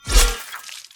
melee-hit-12.ogg